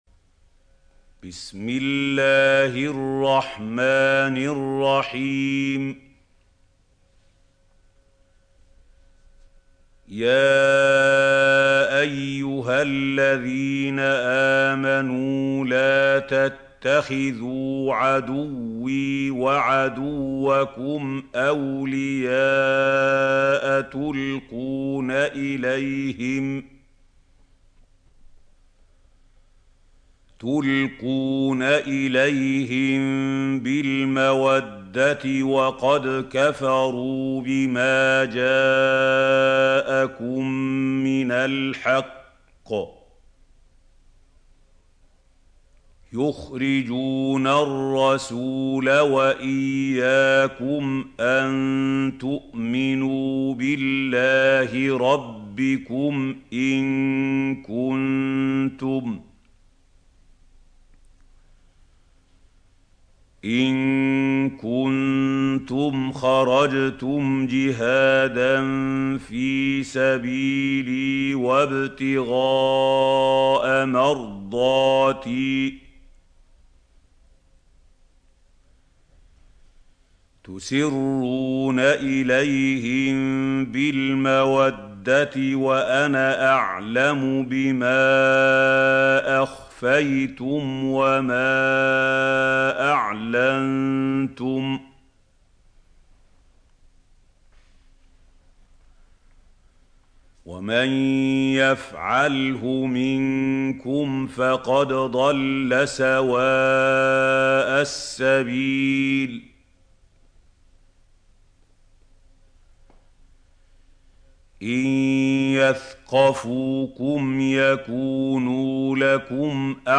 سورة الممتحنة | القارئ محمود خليل الحصري - المصحف المعلم